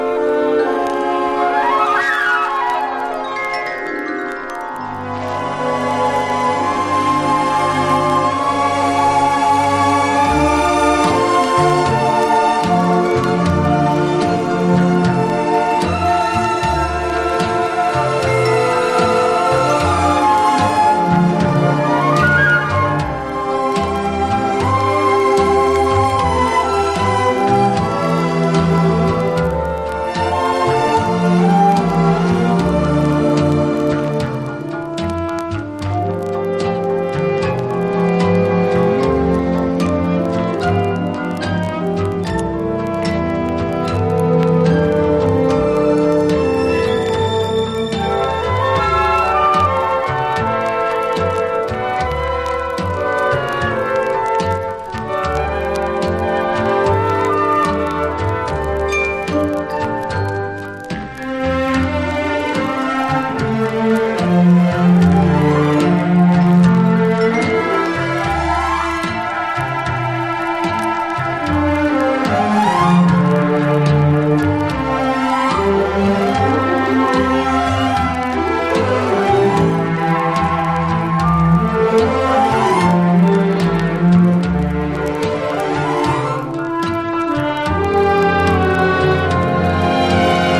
とてつもなく優美でロマンティックな魅惑のストリングス・ラウンジ！